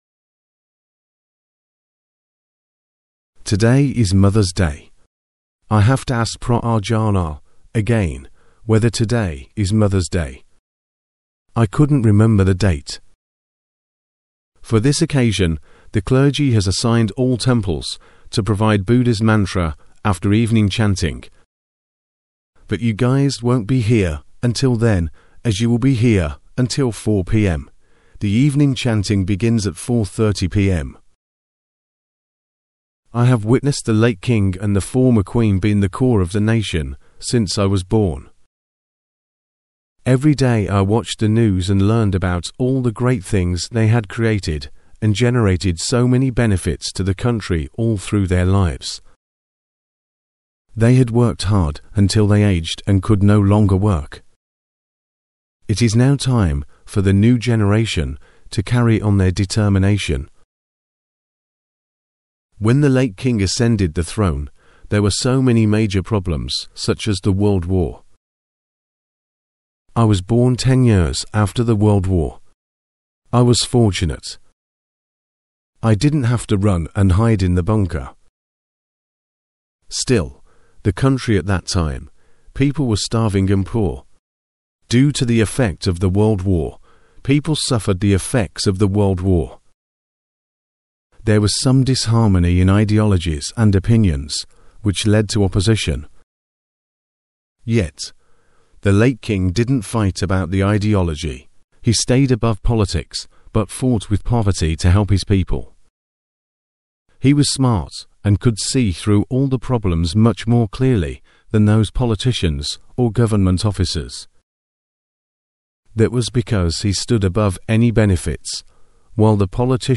Edited and narrated - Dhamma Practice - 12 August 2023